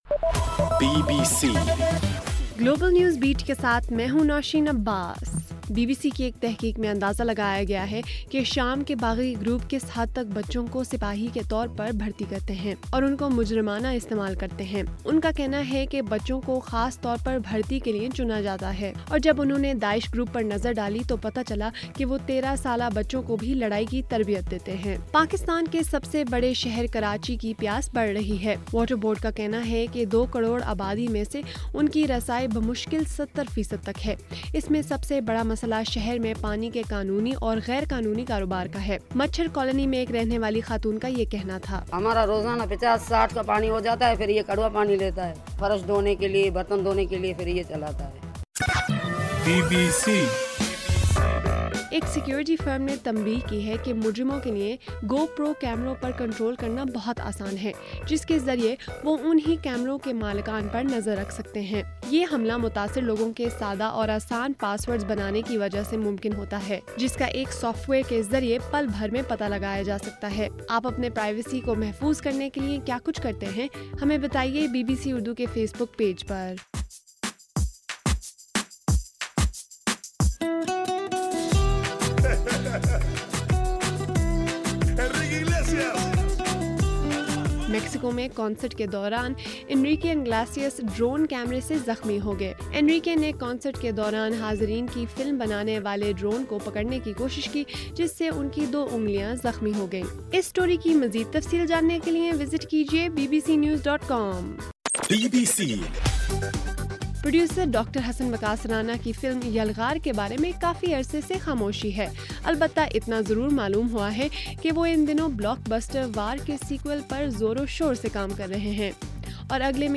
جون 1: رات 8 بجے کا گلوبل نیوز بیٹ بُلیٹن